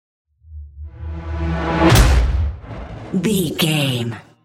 Whoosh to hit electronic
Sound Effects
Atonal
dark
futuristic
intense
tension